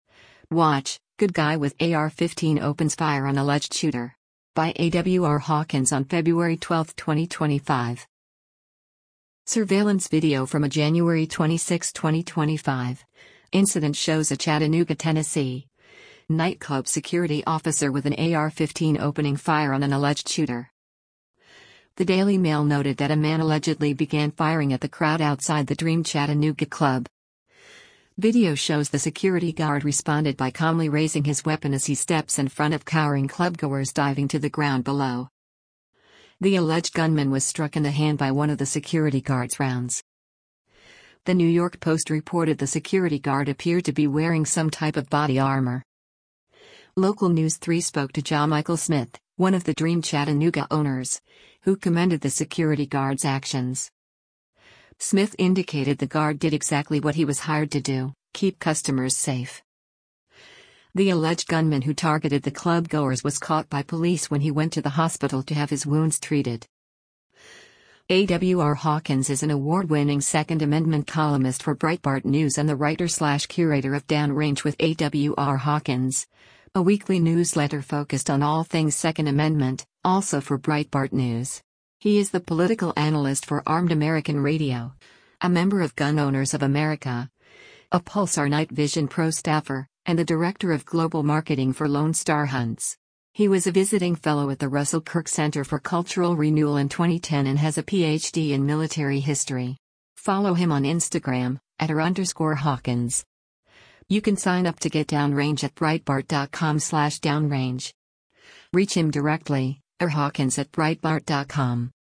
Surveillance video from a January 26, 2025, incident shows a Chattanooga, Tennessee, nightclub security officer with an AR-15 opening fire on an alleged shooter.